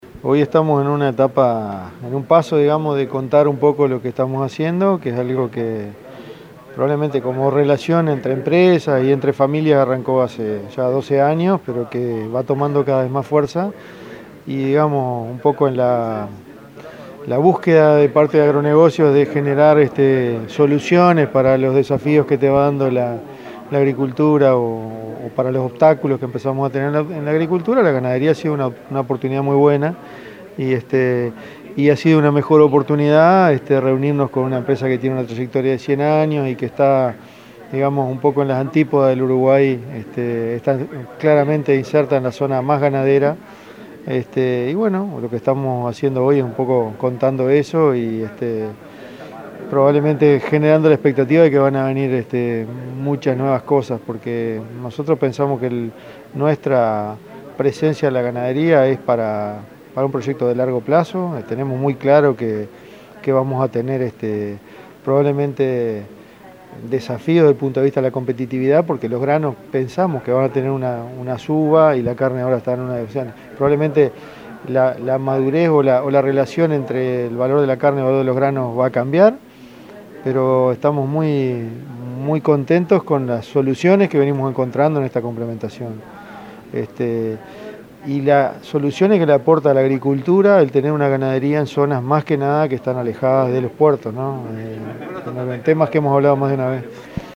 En conferencia de prensa representantes de ambas empresas destacaron las ventajas competitivas que se plantean para los productores en el marco de este acuerdo.